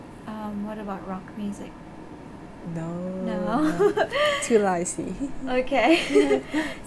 S1 = Brunei female S2 = Chinese female Context: They are talking about tastes in music. S1: erm what about rock music S2: no: S1: no @@ S2: too noisy S1: okay @@ Intended Word: noisy Heard as: loisy Discussion: The initial consonant is [l] rather than [n].
Although from her laughter she seems to understand it, she subsequently reported that she could not in fact understand this word.